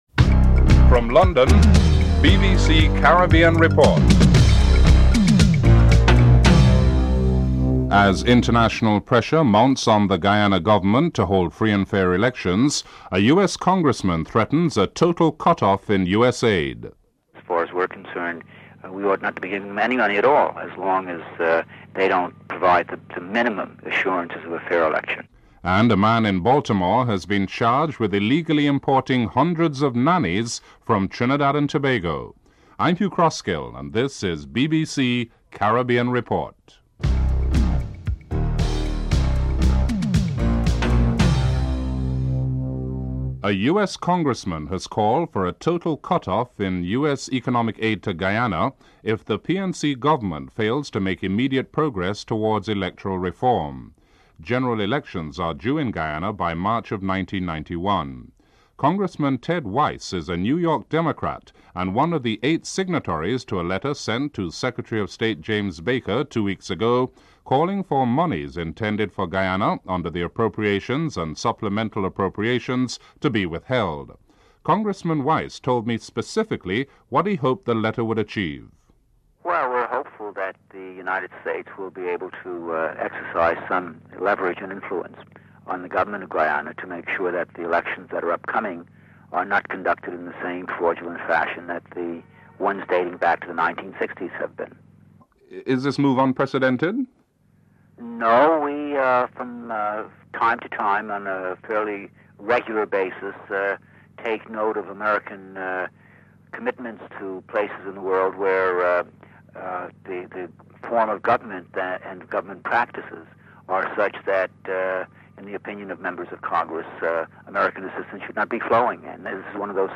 anchor